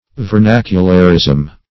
Vernacularism \Ver*nac"u*lar*ism\, n.
vernacularism.mp3